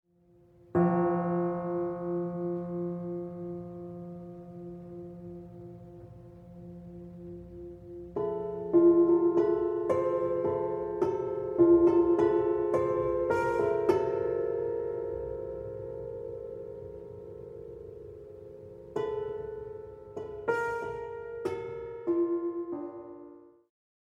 piano
clarinetto